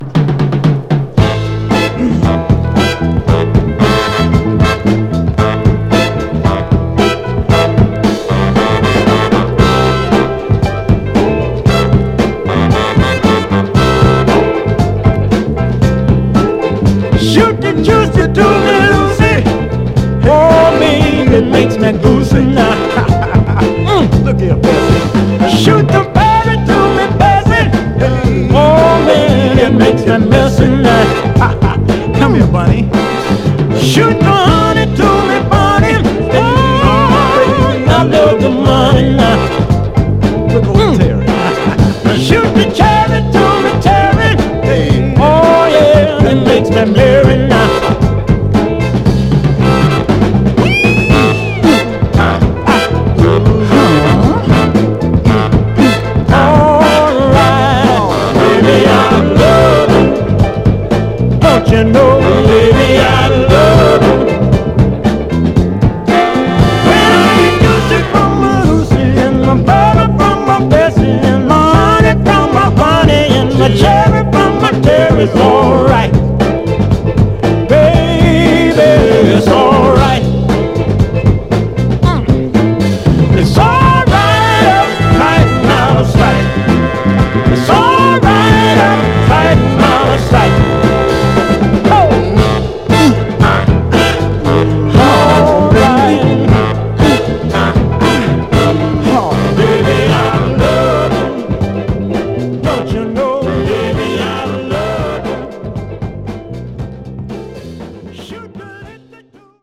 クロスオーヴァー・スタイルのメンフィス・ソウル人気タイトルです！
盤はエッジ中心に細かい表面スレ、細かいヘアーラインキズが目立ちますが、目立つノイズは特になく見た目の割にプレイ良好です。
※試聴音源は実際にお送りする商品から録音したものです※